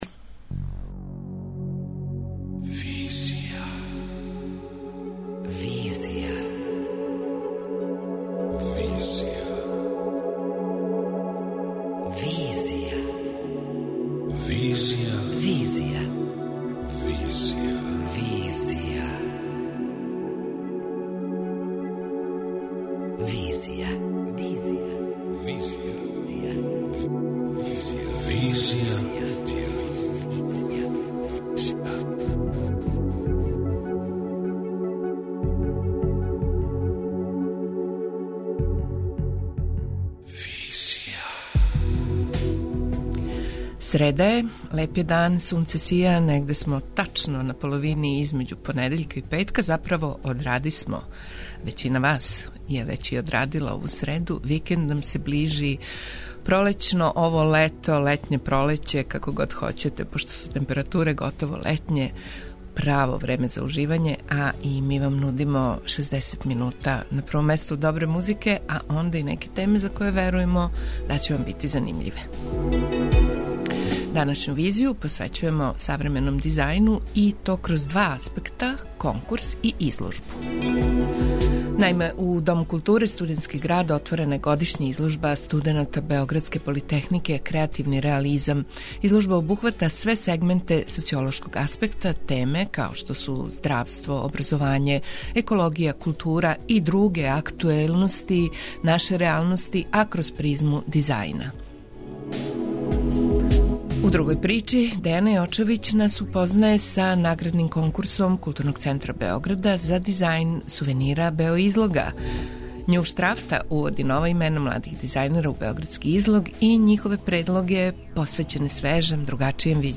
преузми : 27.81 MB Визија Autor: Београд 202 Социо-културолошки магазин, који прати савремене друштвене феномене.